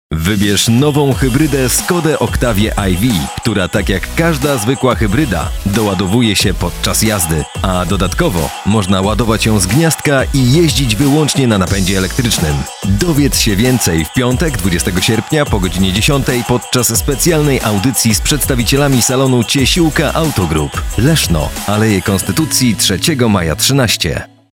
Solid, strong voiceover voice with over 10 years of studio experience.
Spot reklamowy